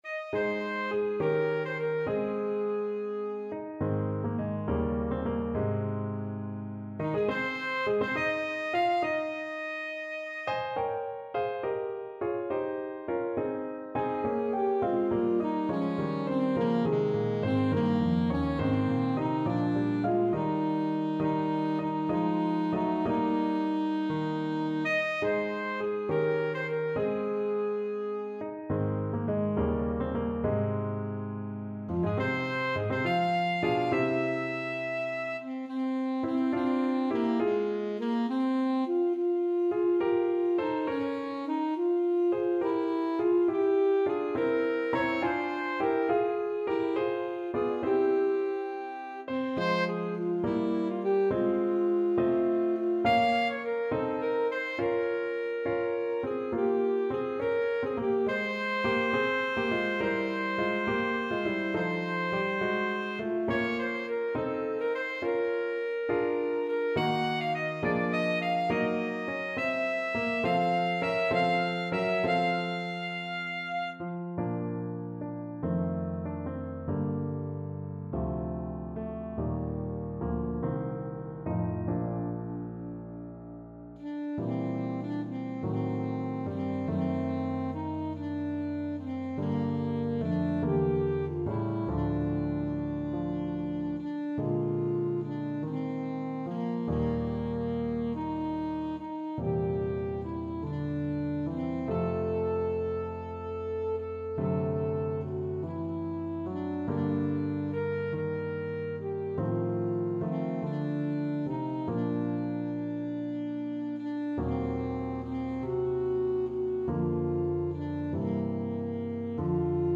Alto Saxophone
Nr. 28 . = 69
12/8 (View more 12/8 Music)
Ab4-F#6
Classical (View more Classical Saxophone Music)